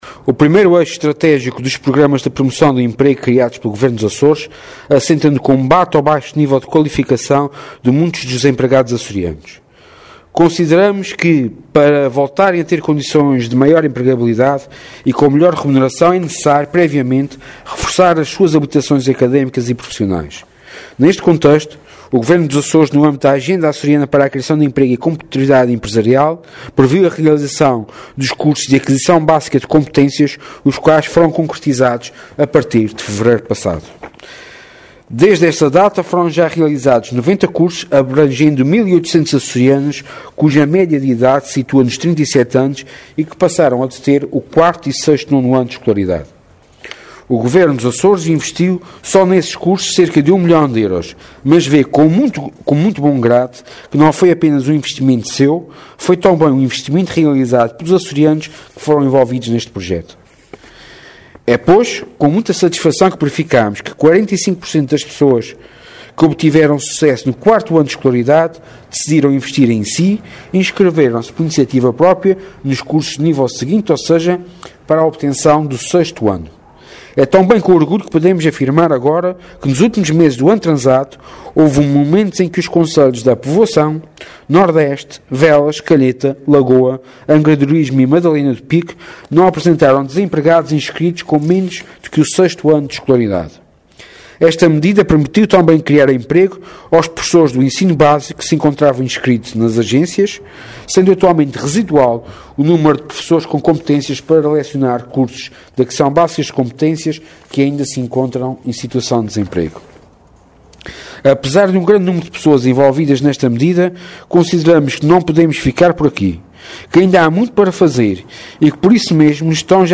Sérgio Ávila, que falava na cerimónia de entrega de diplomas a uma centena de formandos que concluíram cursos ABC realizados na ilha Terceira, aproveitou para fazer um balanço de várias medidas governamentais que visam o aumento da empregabilidade.